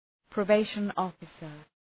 probation-officer.mp3